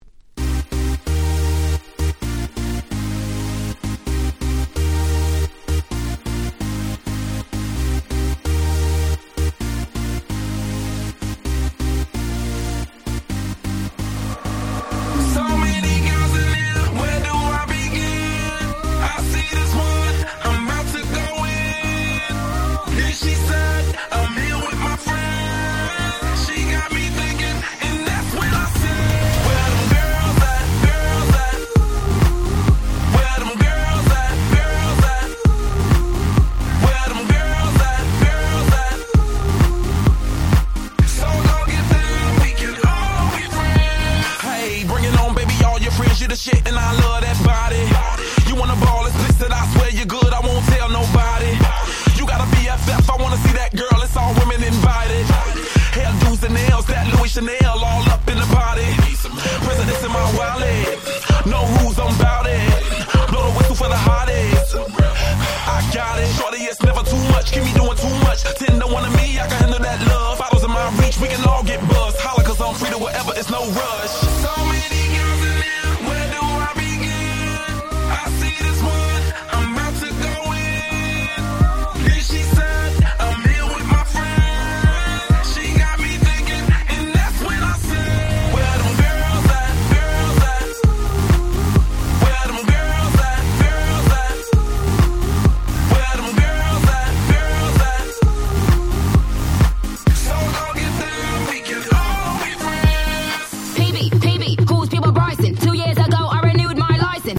11' Super Hit EDM / R&B / Hip Hop !!